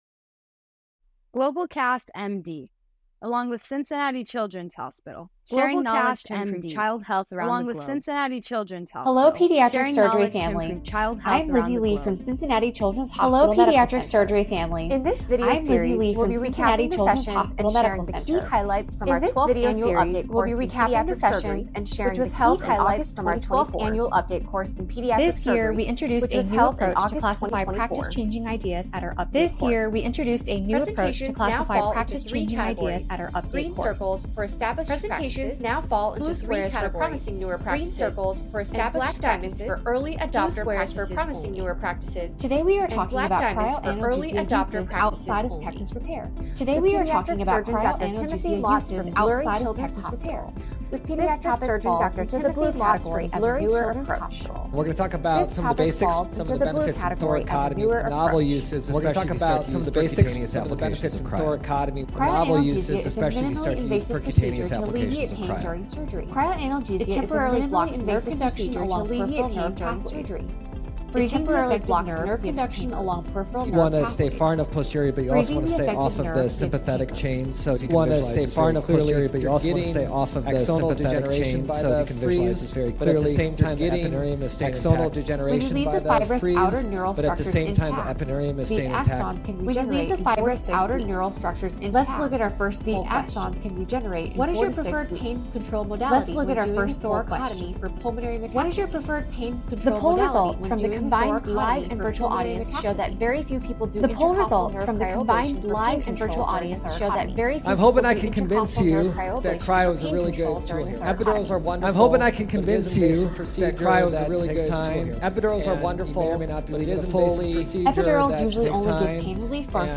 In this engaging session from the 12th Annual Update Course in Pediatric Surgery